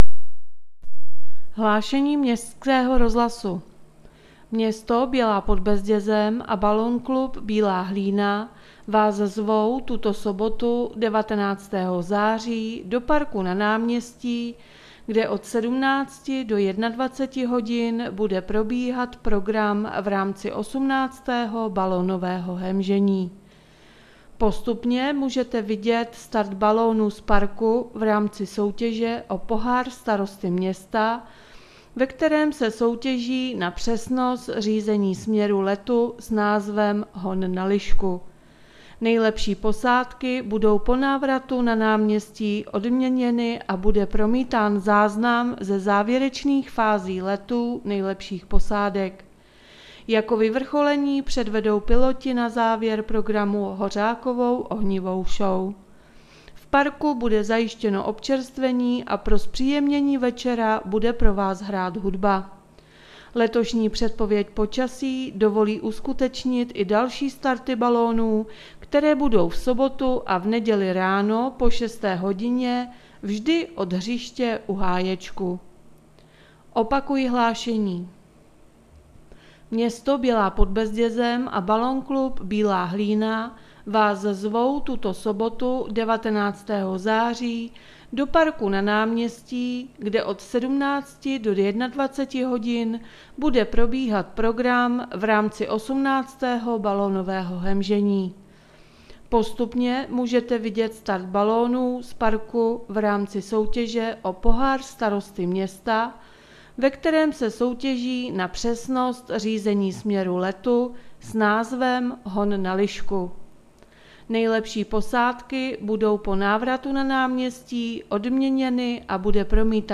Hlášení městského rozhlasu 18.9.2020